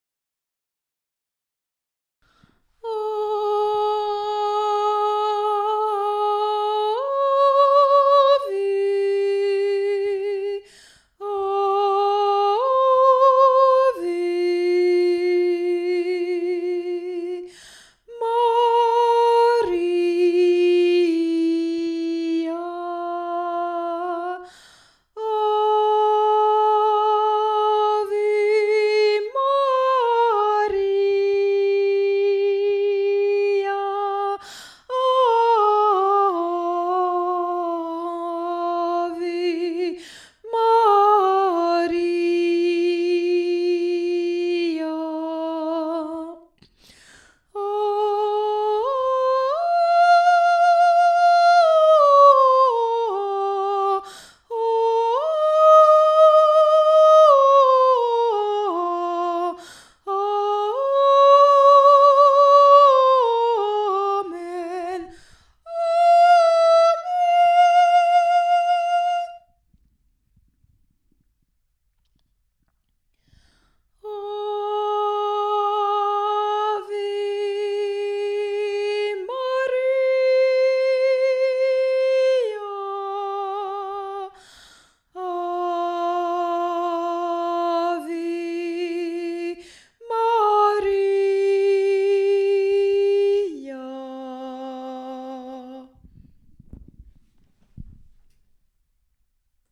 ob_d2ef58_ave-maria-soprano.mp3